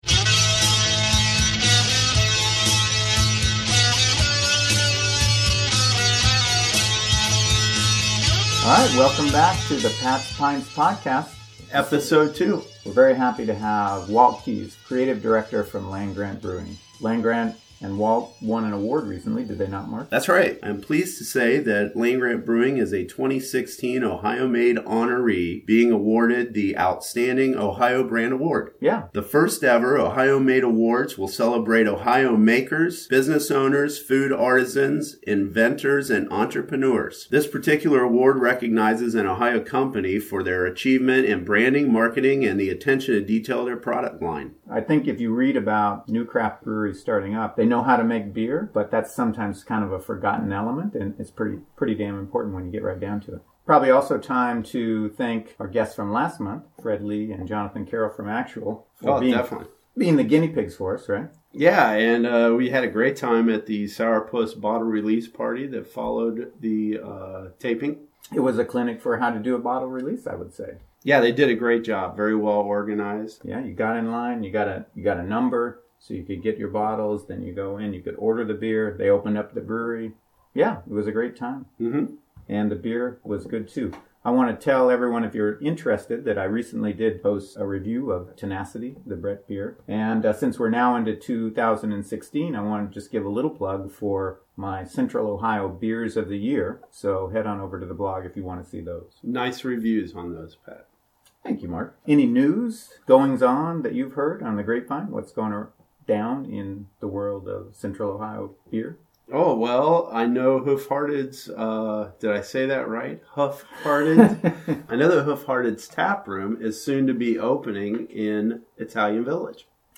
most of the inebriated rambling has been edited out, the effects of the alcohol become harder to hide as the podcast progresses